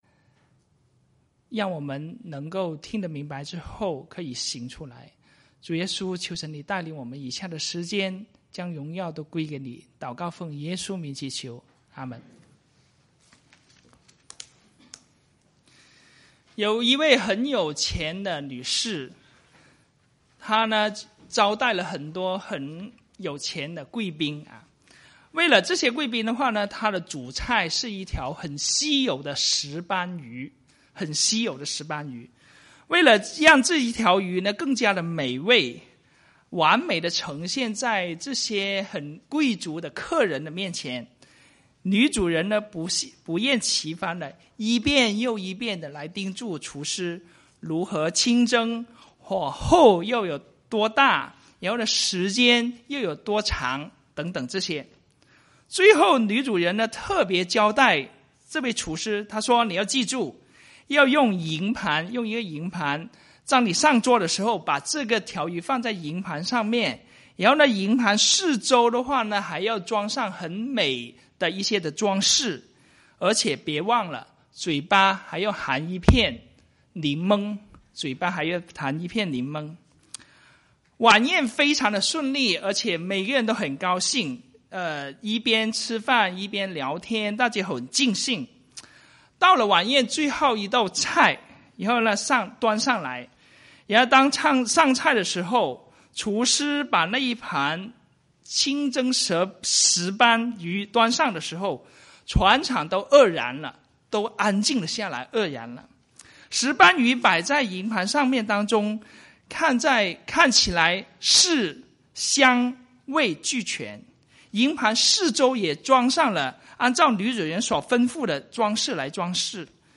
Sermons | Fraser Lands Church